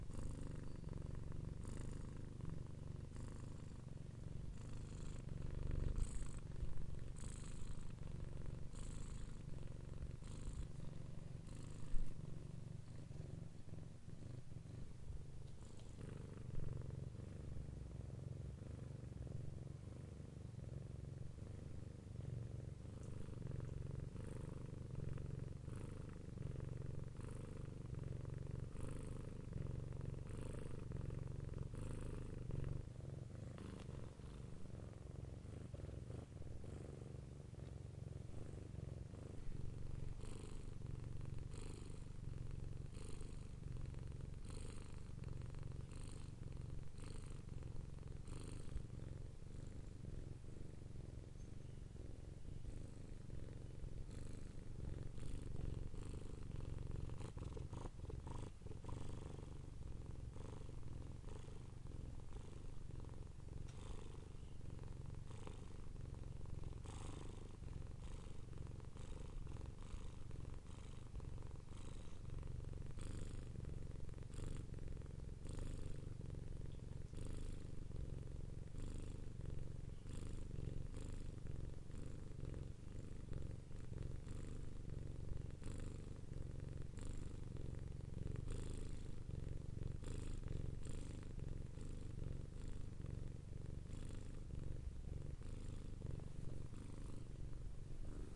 猫的呼噜声
描述：猫用单声道咕噜声记录
Tag: 宠物 打呼噜 宠物 国内 动物 动物 呼噜声